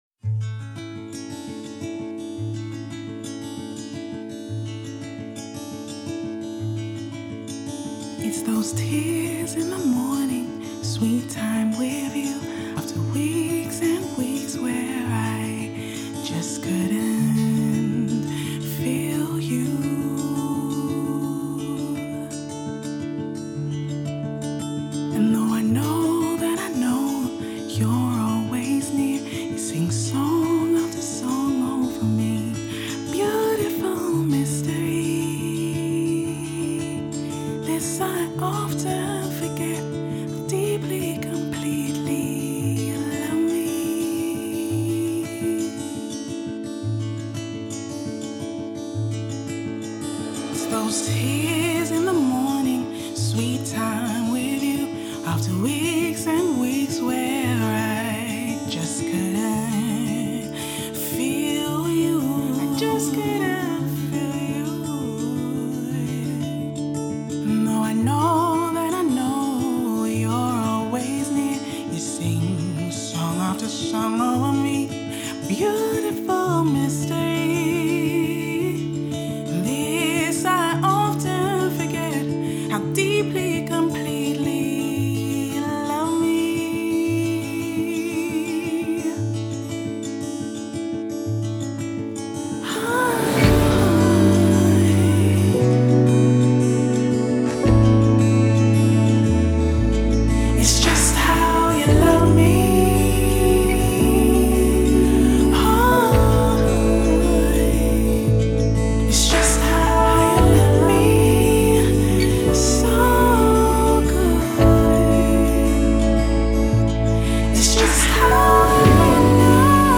Recorded in two days in a living room in South London